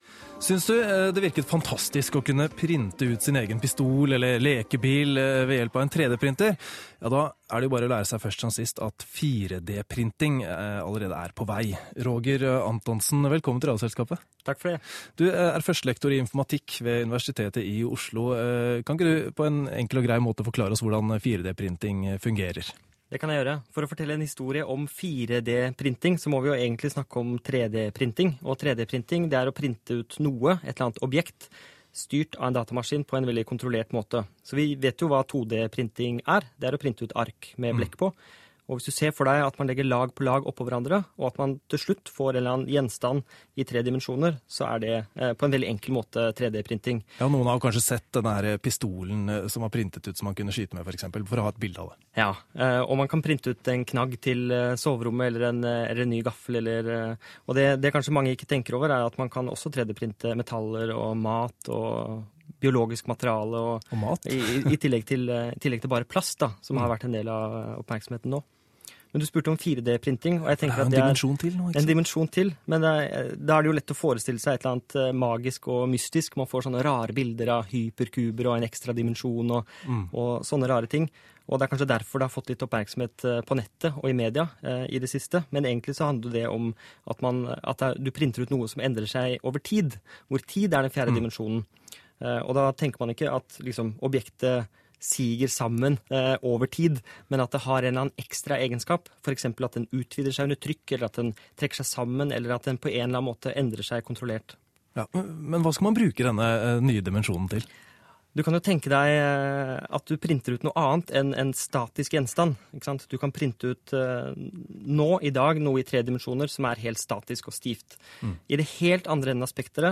Presse og media 17. juni 2013, kl. 11.03 NRK P2, Radioselskapet intervju på radio